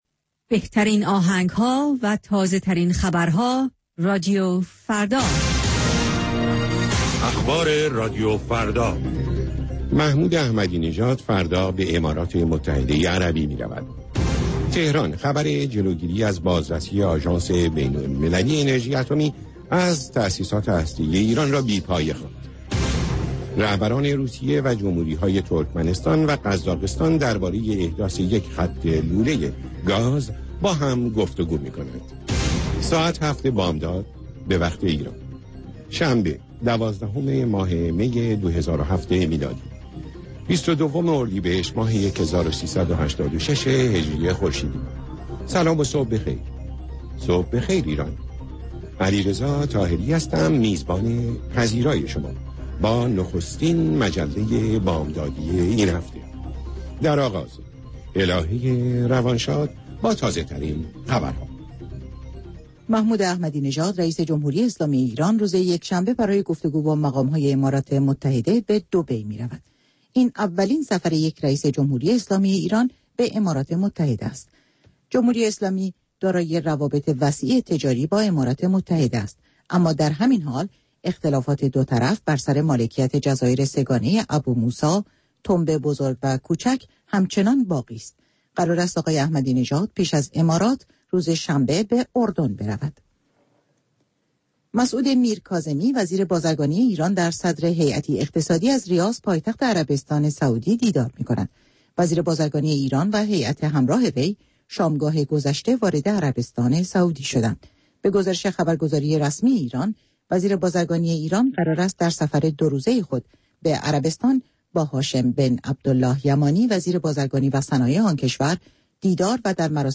گزارشگران راديو فردا از سراسر جهان، با تازه ترين خبرها و گزارش ها، مجله ای رنگارنگ را برای شما تدارک می بينند. با مجله بامدادی راديو فردا، شما در آغاز روز خود، از آخرين رويدادها آگاه می شويد.